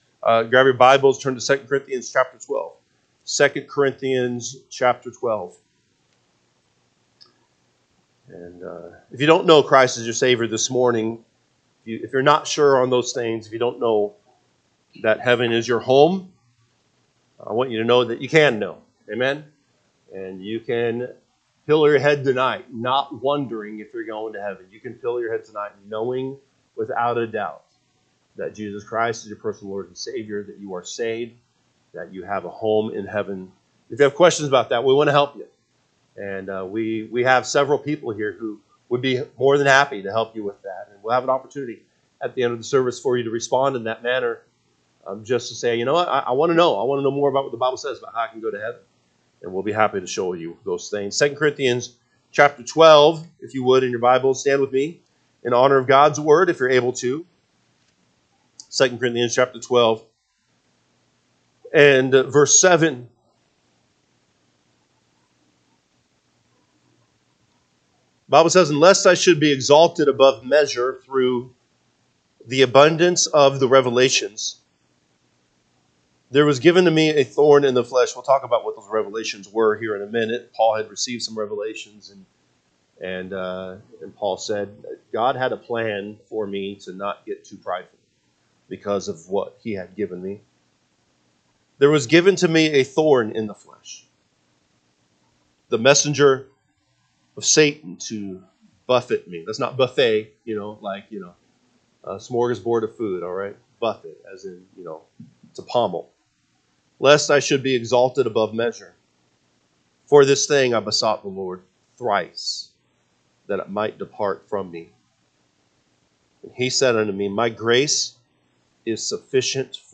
March 23, 2025 am Service 2 Corinthians 12:7-10 (KJB) 7 And lest I should be exalted above measure through the abundance of the revelations, there was given to me a thorn in the flesh, the mes…